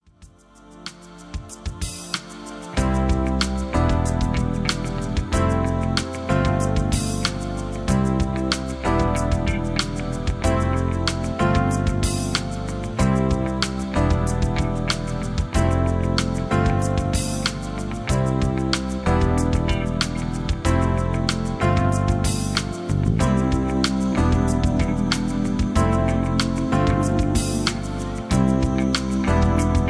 Key-C
Just Plain & Simply "GREAT MUSIC" (No Lyrics).
mp3 backing tracks